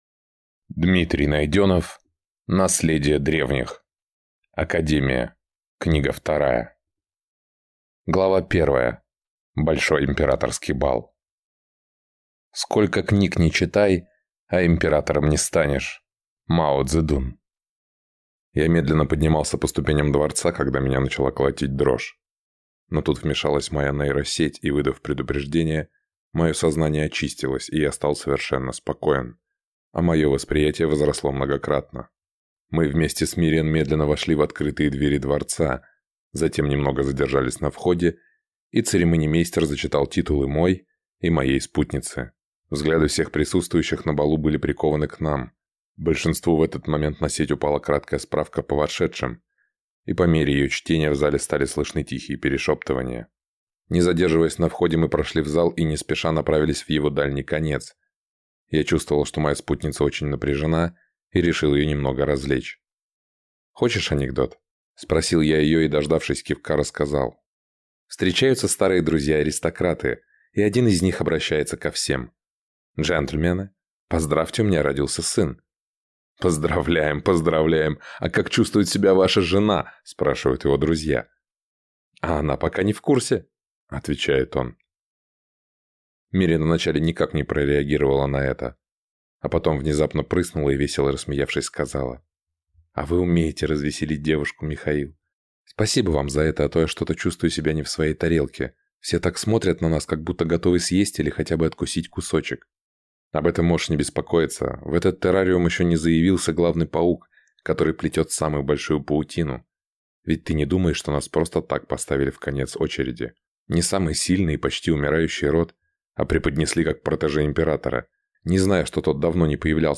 Аудиокнига Наследие Древних. Академия. Книга вторая | Библиотека аудиокниг